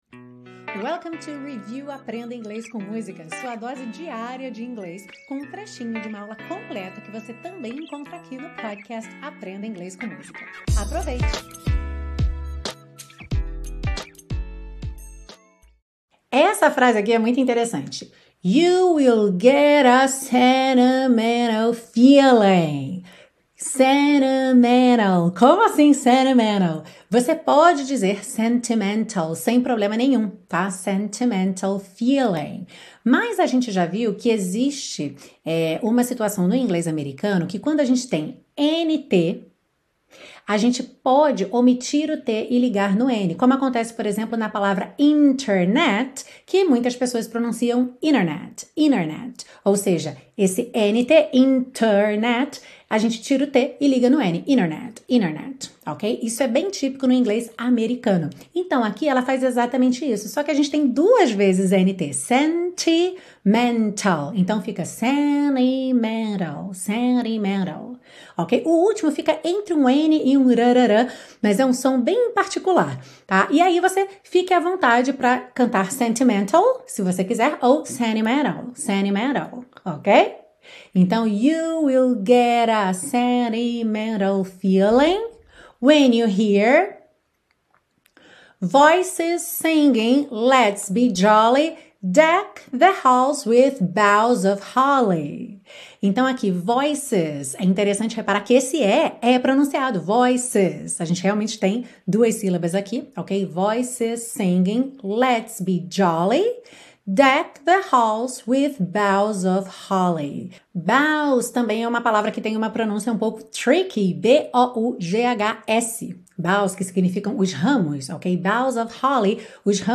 Este é só um trecho da aula completa da música "Rockin' Around the Christmas Tree", que você encontra aqui no podcast "Aprenda Inglês com Música".